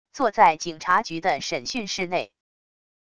坐在警察局的审讯室内wav音频生成系统WAV Audio Player